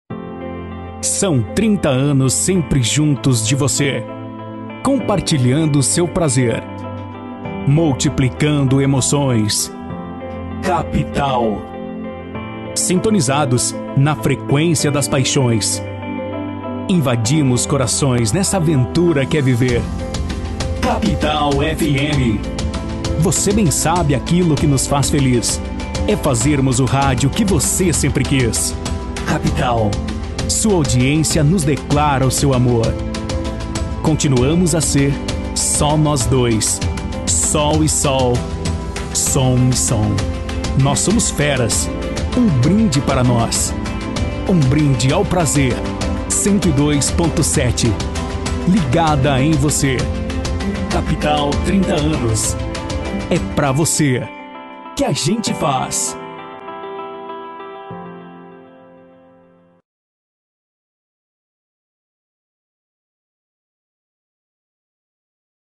DEMONTRATIVO ESTILO IMPACTO :